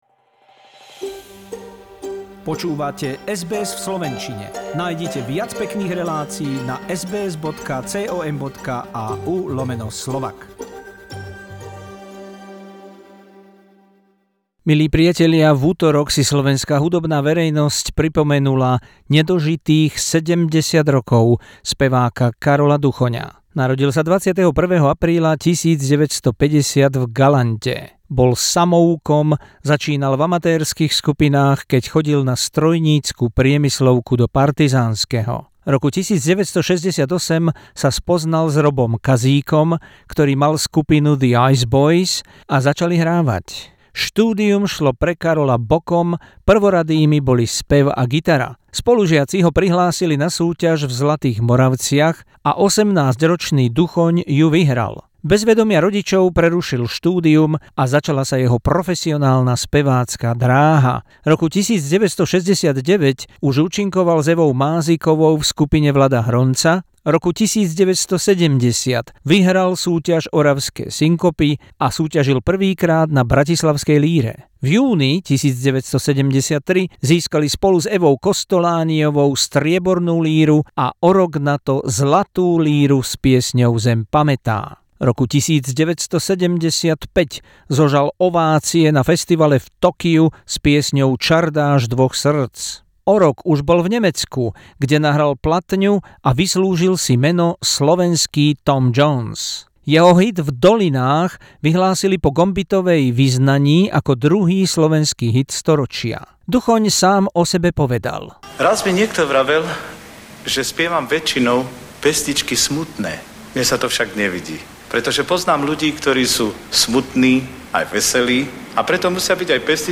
Short feature about the legendary Slovak singer Karol Duchon who died young, 35, and today he would double it -70...